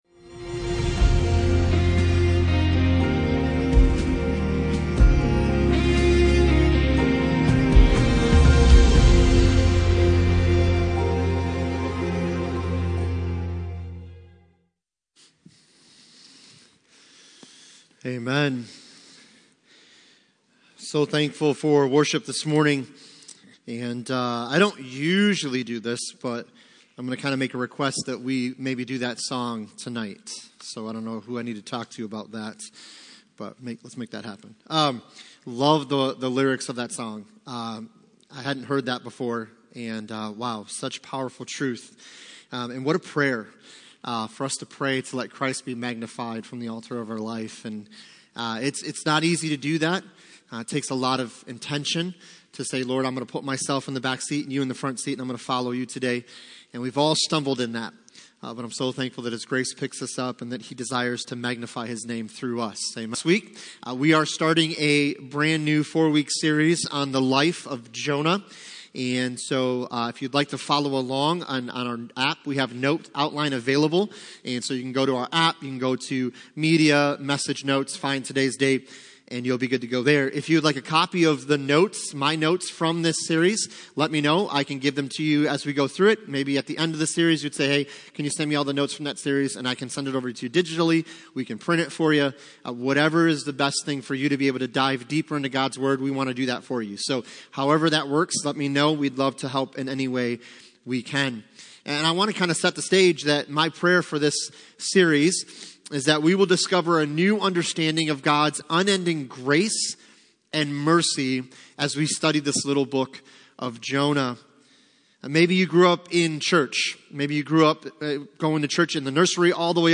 Passage: Jonah 1:1-3 Service Type: Sunday Morning